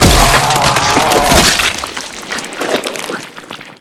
spacewormdie.ogg